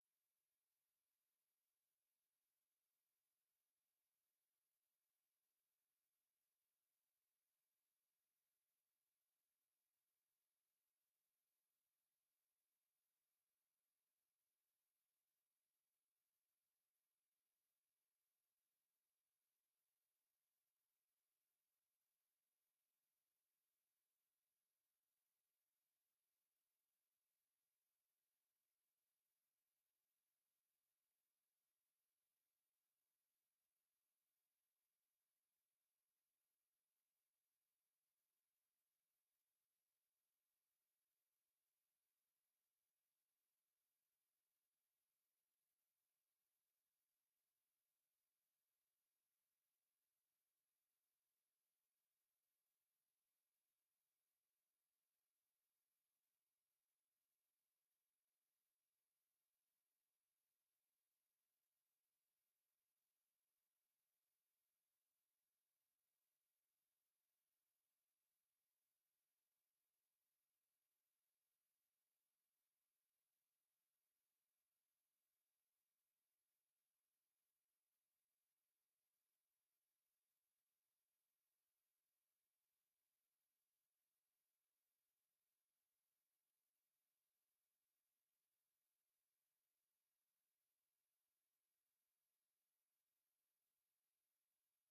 informatieve raadsbijeenkomst 17 oktober 2024 19:30:00, Gemeente Doetinchem